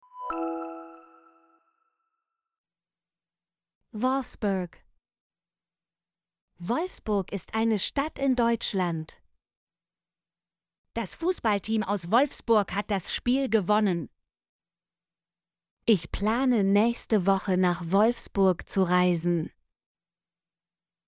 wolfsburg - Example Sentences & Pronunciation, German Frequency List